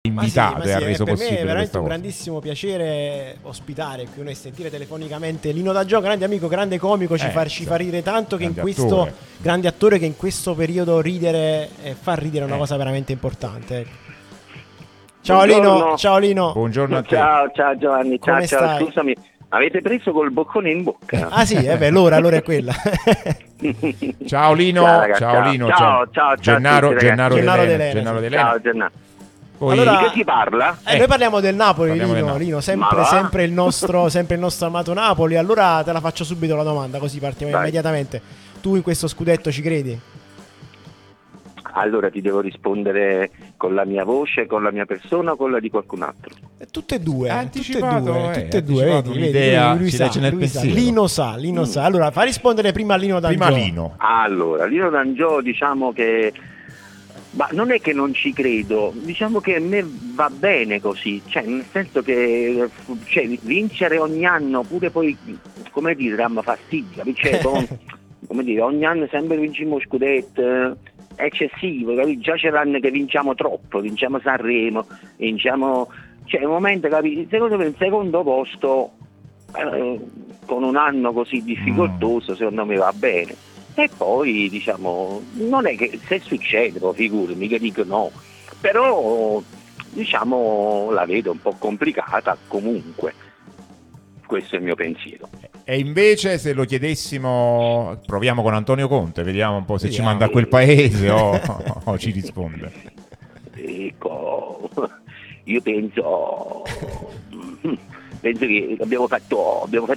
comico